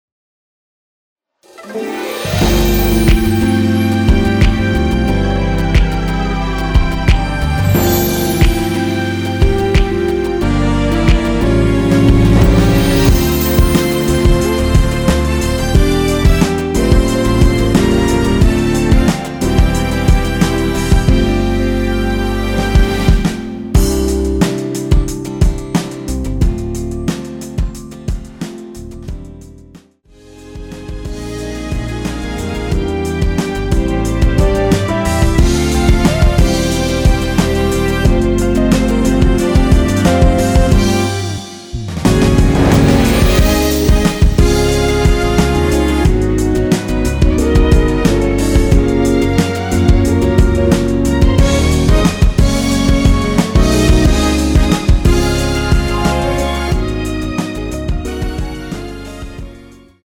원키에서(-3)내린 MR입니다.
앞부분30초, 뒷부분30초씩 편집해서 올려 드리고 있습니다.
중간에 음이 끈어지고 다시 나오는 이유는